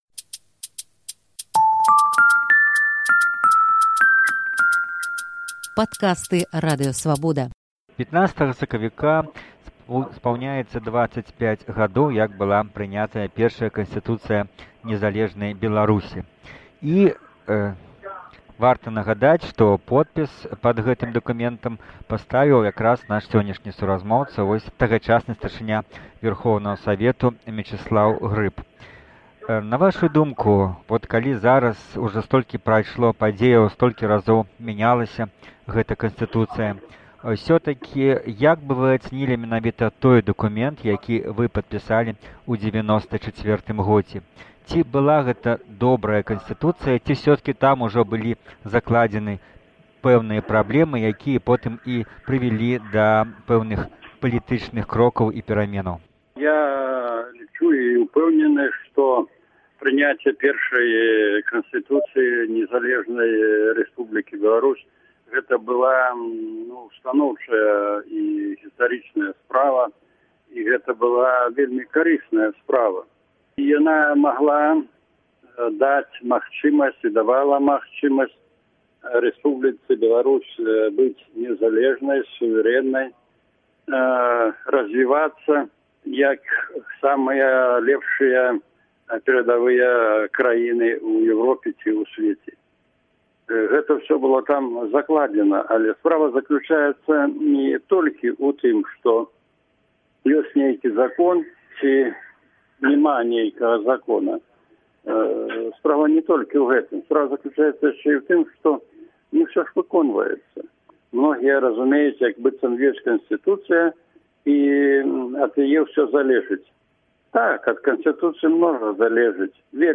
размаляе з падпісантам асноўнага закону краіны, былым кіраўніком Вярхоўнага савету Беларусі Мечыславам Грыбам